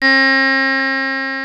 HOHNER 1982 4.wav